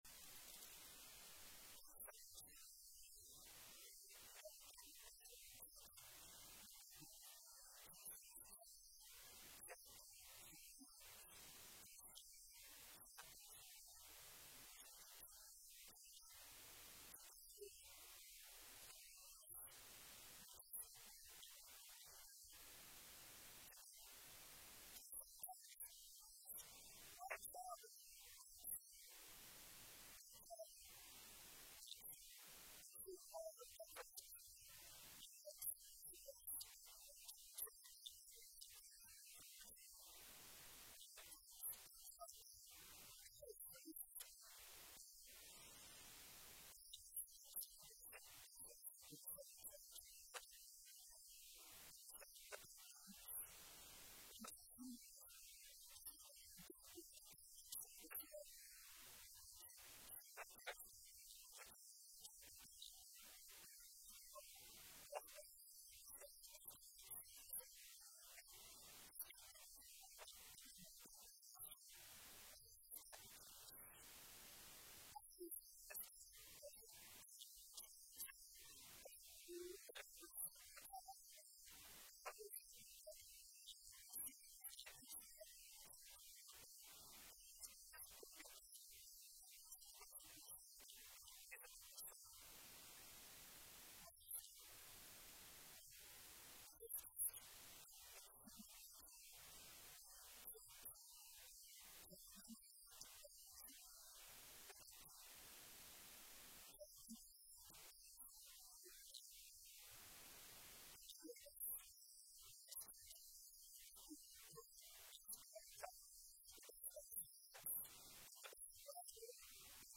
March 1, 2026 Sermon Audio.mp3